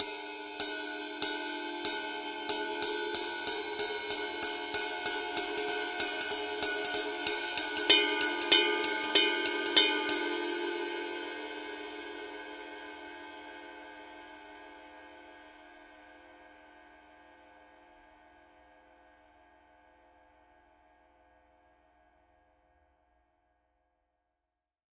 Тарілка райд 24"
RUDE continues to be the leading choice of sound for raw, merciless and powerful musical energy in Rock, Metal, and Punk.
24_mega_power_ride_pattern.mp3